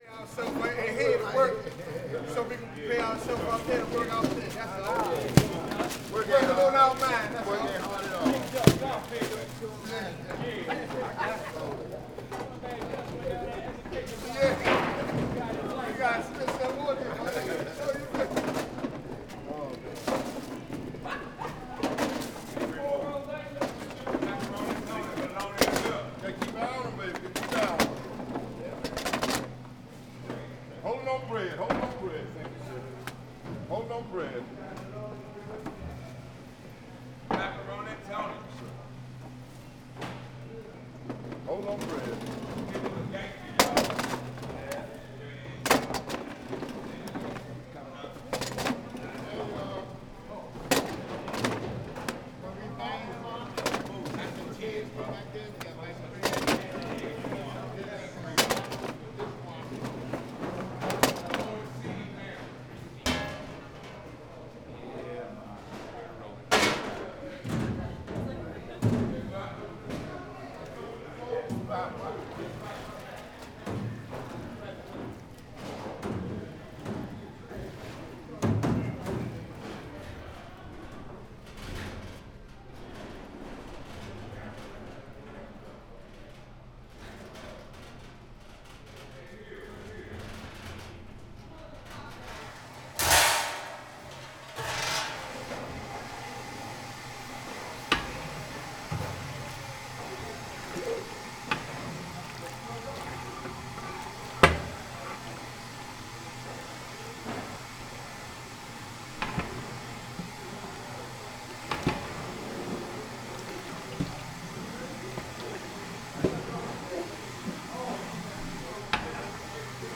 Sound file 1.1 Food preparation in the kitchen